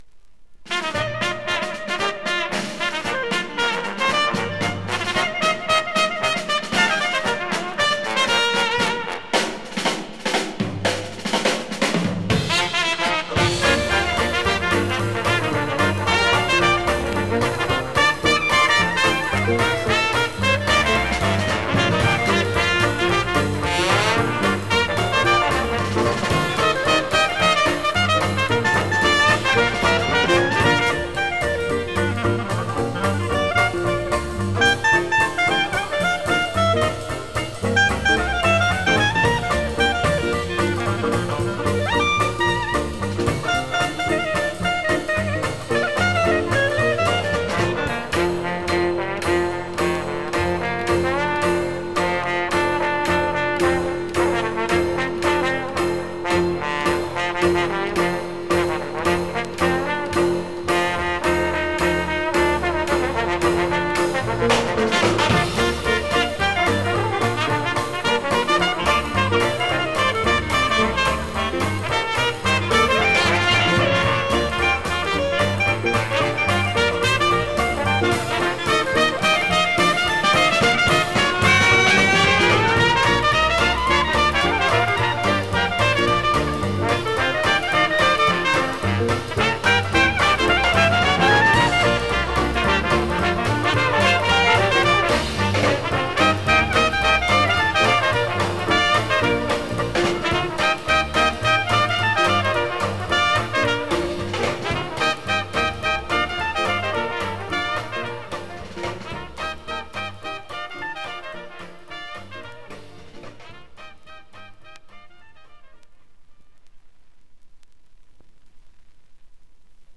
Жанр: Dixieland